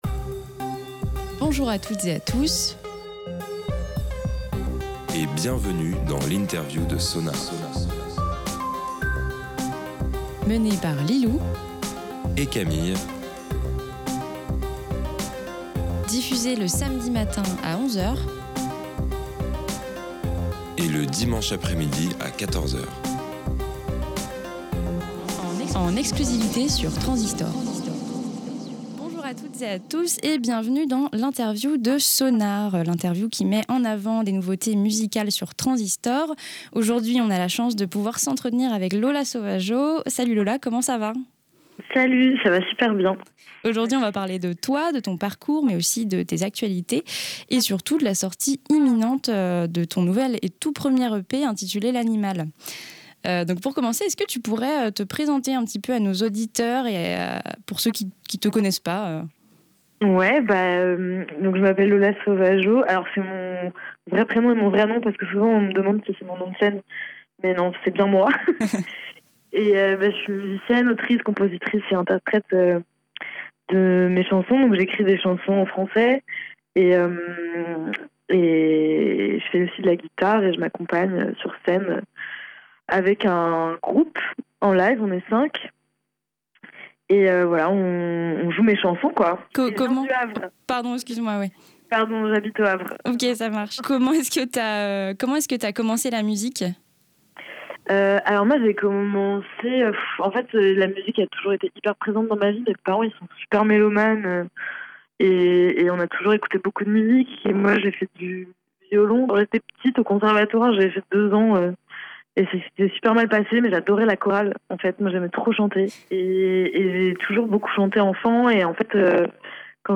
SONAR x INTERVIEW "L'Animale"